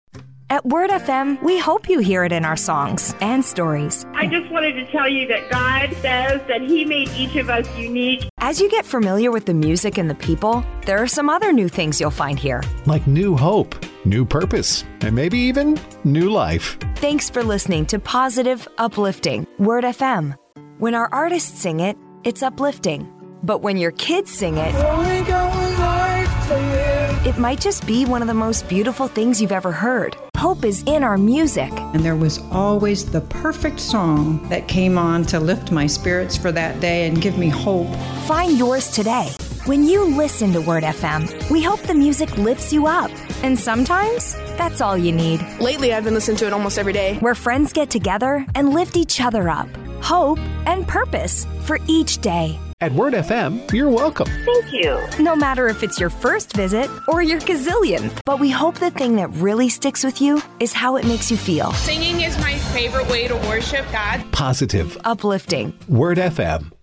English (Canadian)
Radio Imaging
ContraltoLowMezzo-Soprano
YouthfulBrightFriendlyConversationalCorporateInformalApproachableSoothingLuxuriousNurturingRaspyDynamicDramaticSincereSensualAuthoritativeArticulateRelatableTrustworthyYoungVersatileConfidentUpbeat